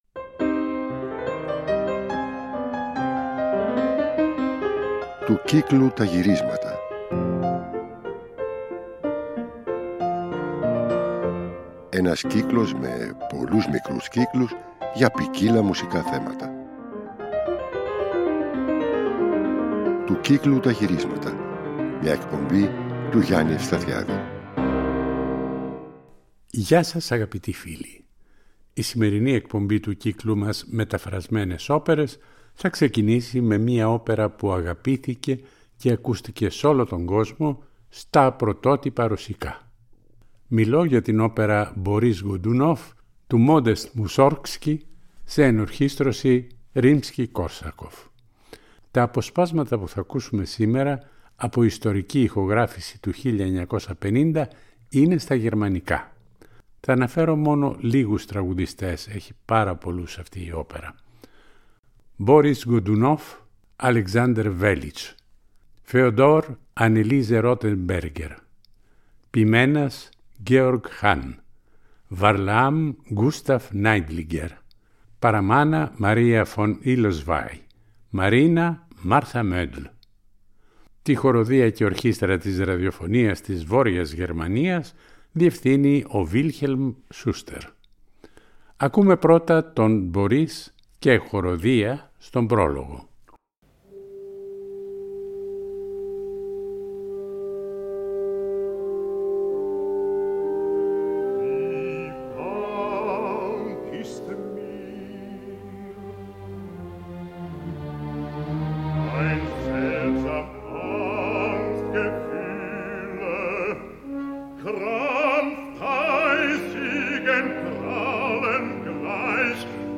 ρώσικη όπερα
άριες